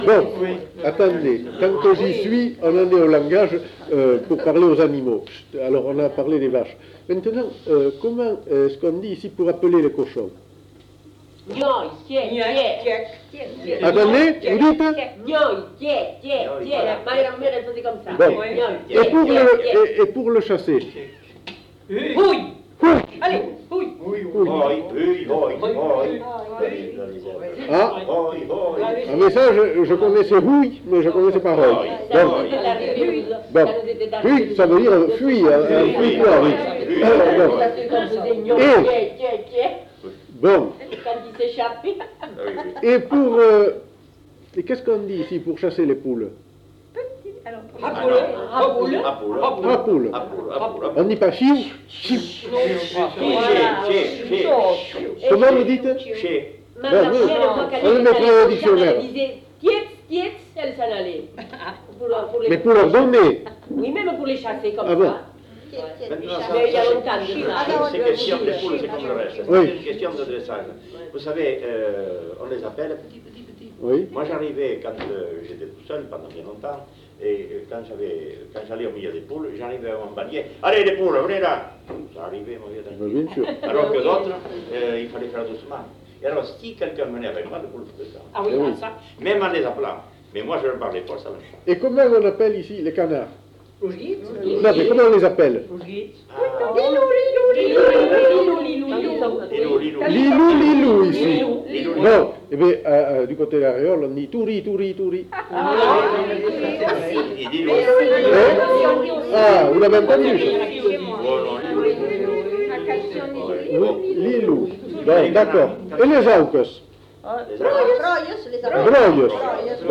Appels au bétail
Lieu : Bazas
Genre : expression vocale
Effectif : 2
Type de voix : voix d'homme ; voix de femme
Production du son : crié